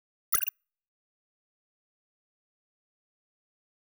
Futurisitc UI Sound 20.wav